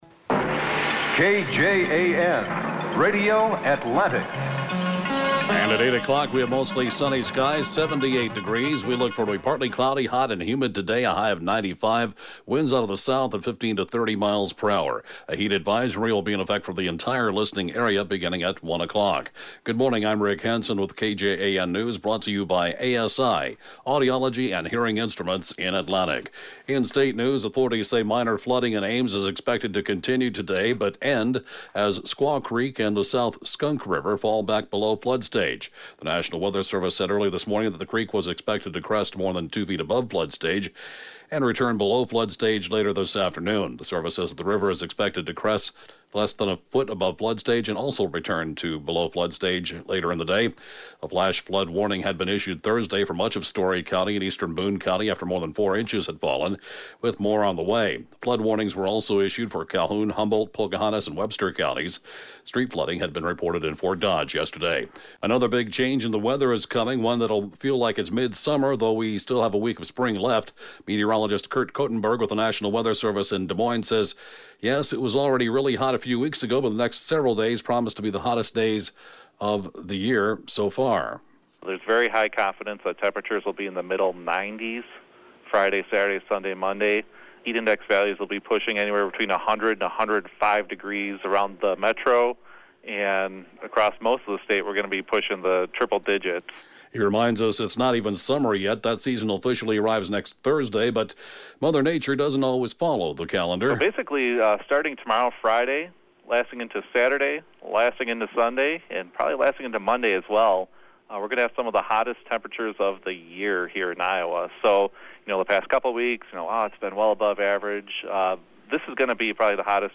(Podcast) KJAN Morning News & funeral report, 1/4/2017